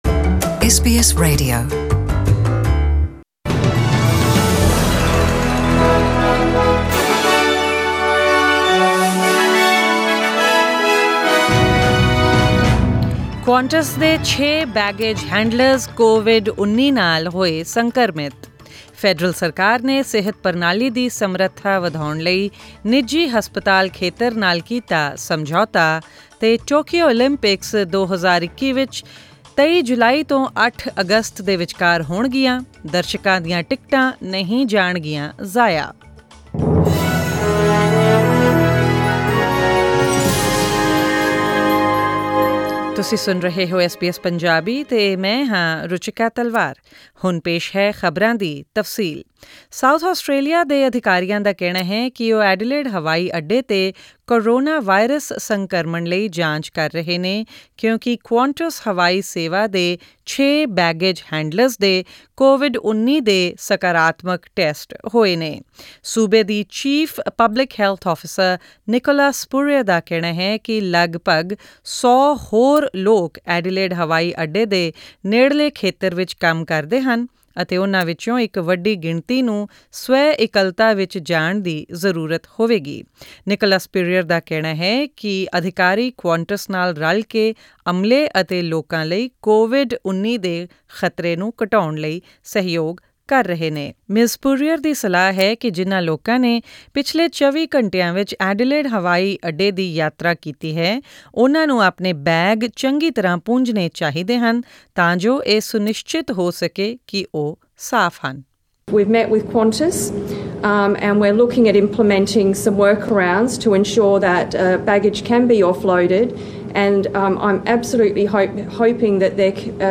Australian News in Punjabi: 31 March 2020